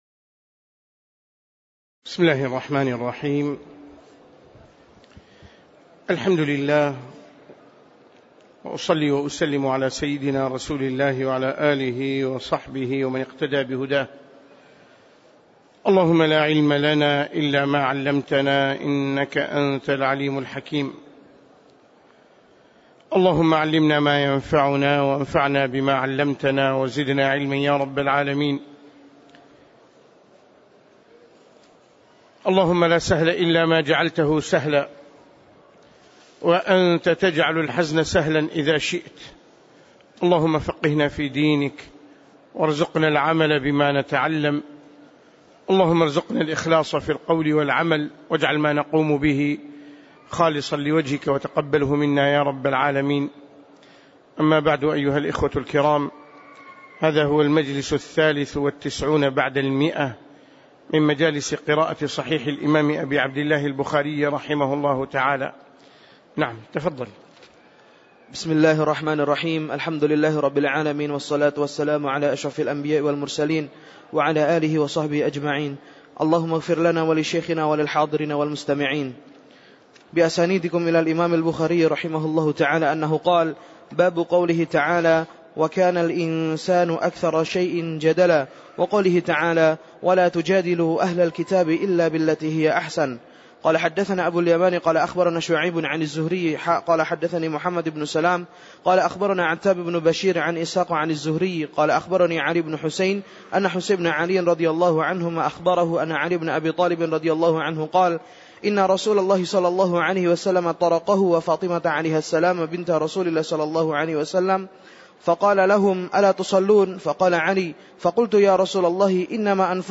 تاريخ النشر ٧ جمادى الأولى ١٤٣٩ هـ المكان: المسجد النبوي الشيخ